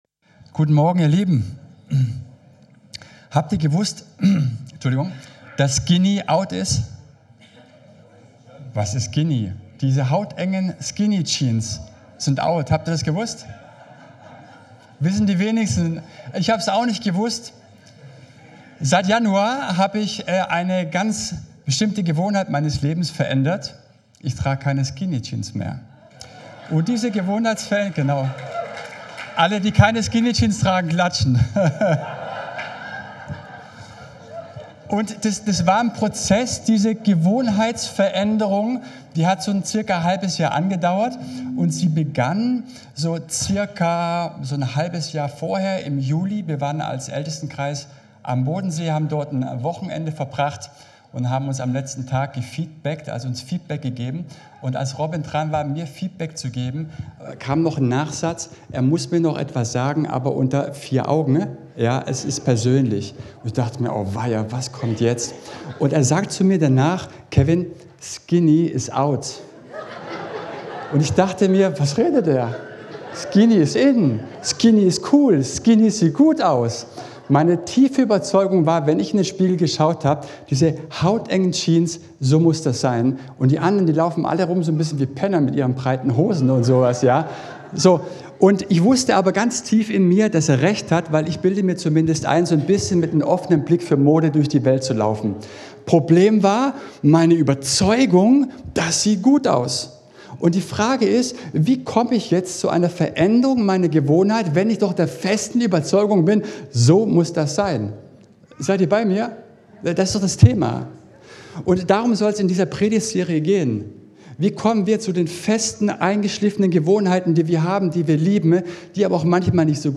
Dienstart: Gottesdienst